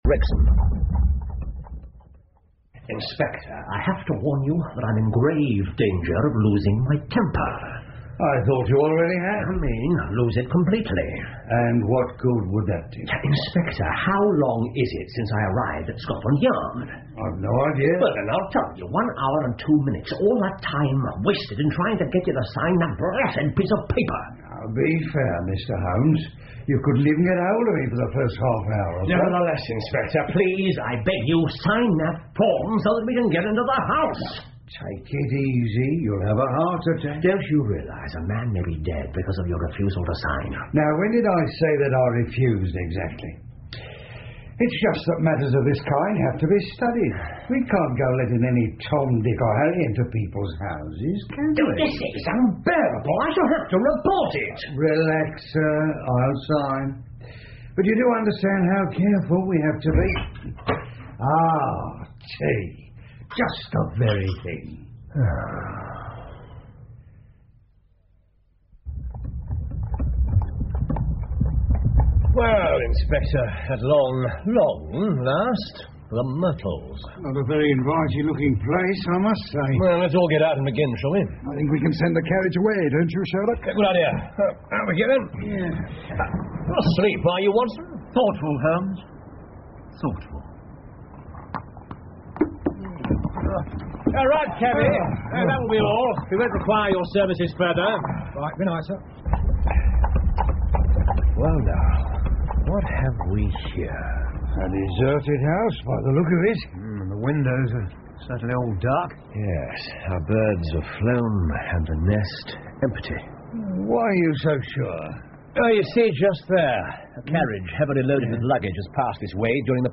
福尔摩斯广播剧 The Greek Interpreter 7 听力文件下载—在线英语听力室